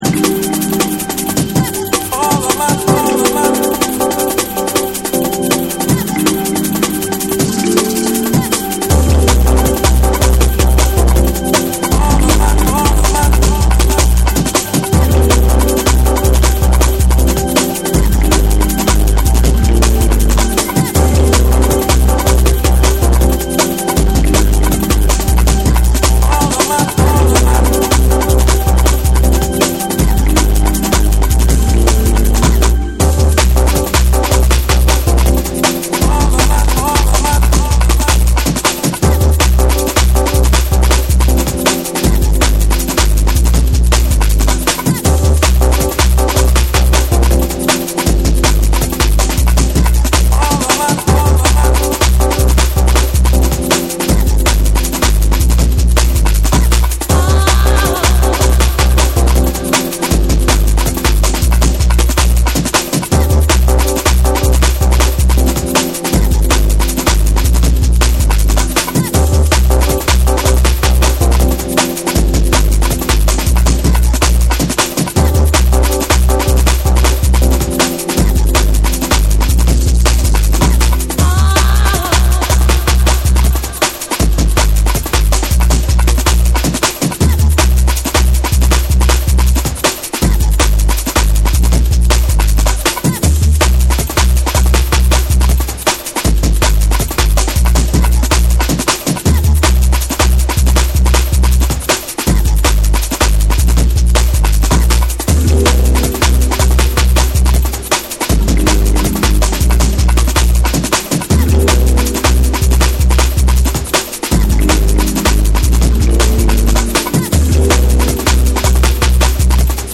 ディープでドリーミーなパッドと硬質ブレイクが交差するフローティングなジャングル・トラック